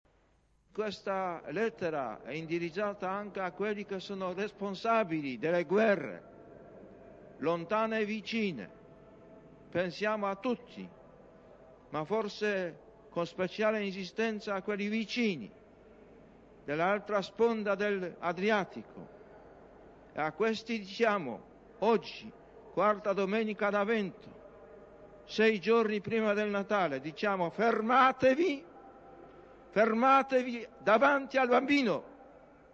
Brano dell'Angelus del 18 dicembre 1994 - IV Domenica di Avvento